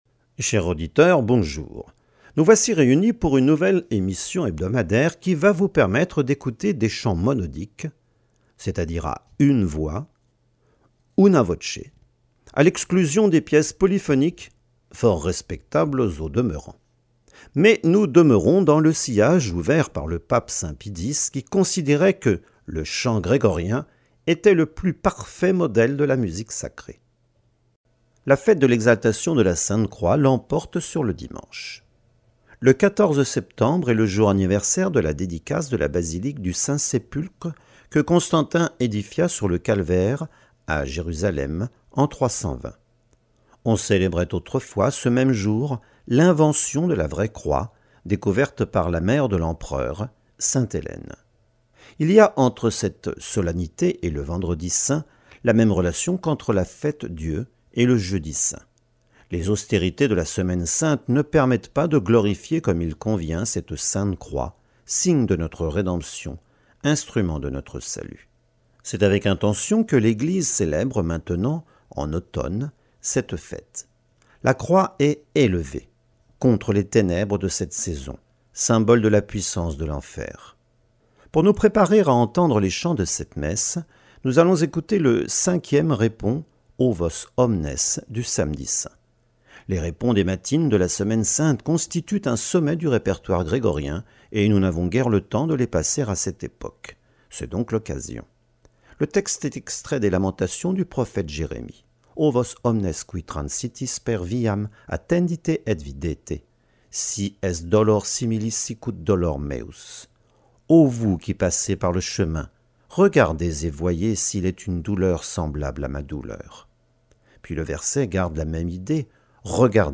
emission_radio_exaltation_sainte_croix_2025.mp3